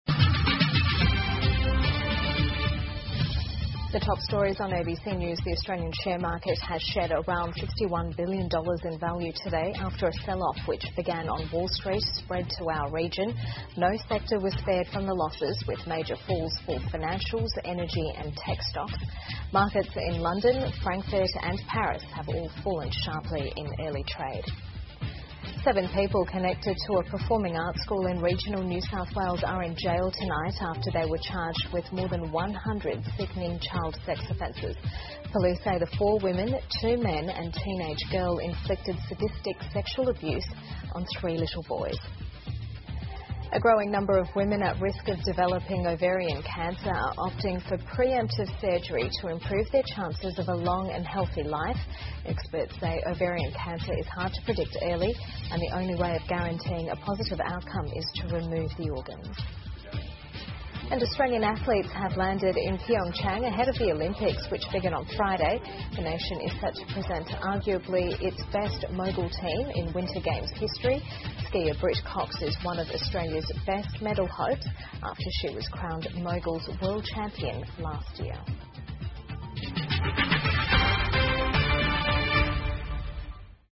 澳洲新闻 (ABC新闻快递) 美股暴跌引发全球股市动荡 选择卵巢癌预防性手术女性增加 听力文件下载—在线英语听力室